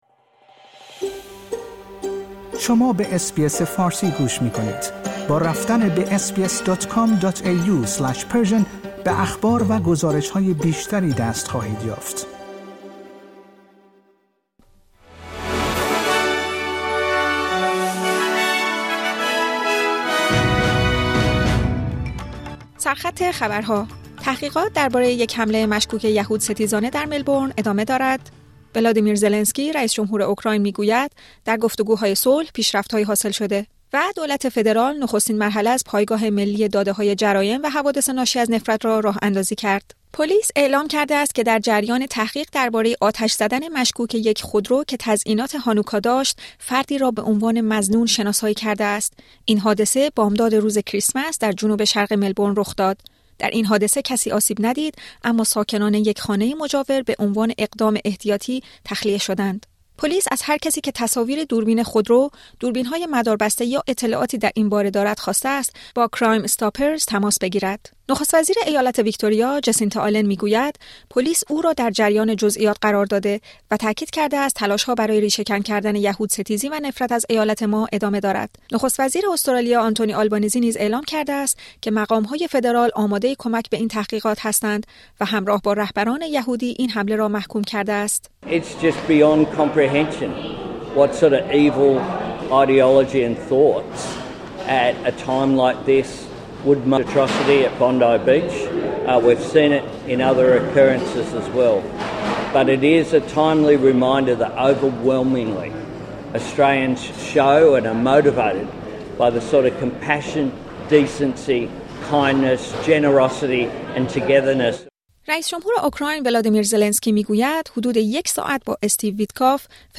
در این پادکست خبری مهمترین اخبار هفته منتهی به جمعه ۲۶ دسامبر ۲۰۲۵ ارائه شده است.